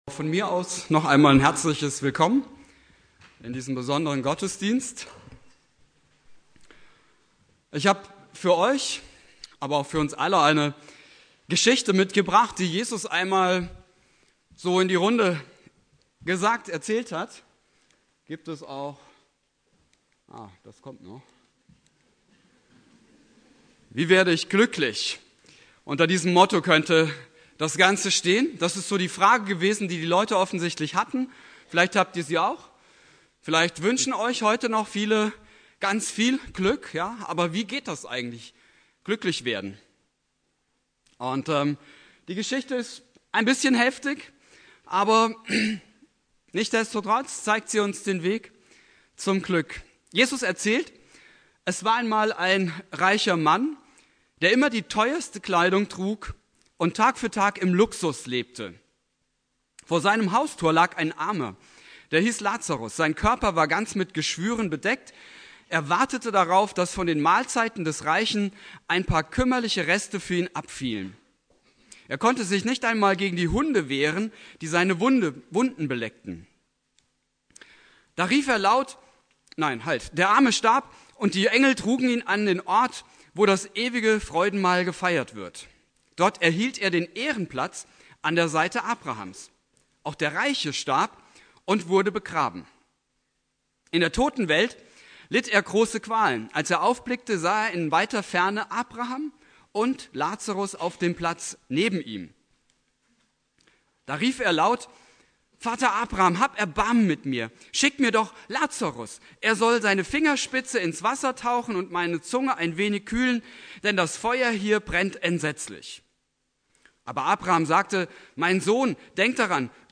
Predigt
Thema: "Wie werde ich glücklich?" (Konfirmationsgottesdienst) Bibeltext: Lukas 16,19-31 Dauer: 18:14 Abspielen: Ihr Browser unterstützt das Audio-Element nicht.